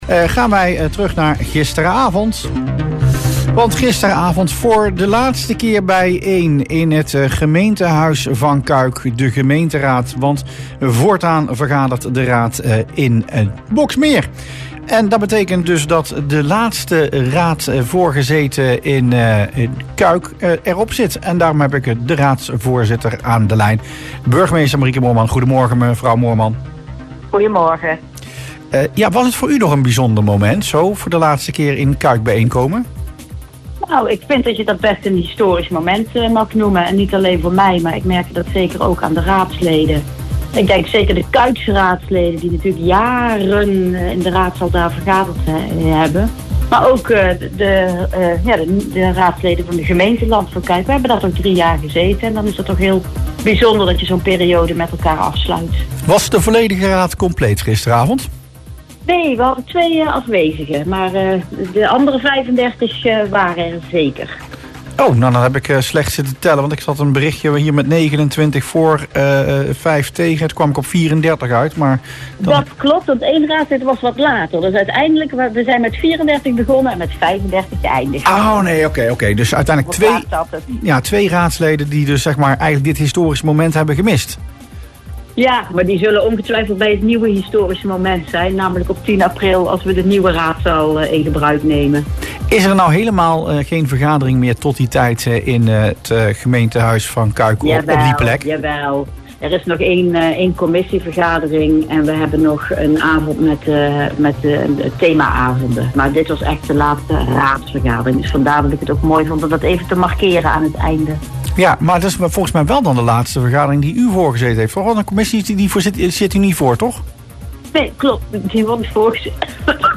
Burgemeester Moorman over laatste raad in Cuijk (in De Ochtendclub)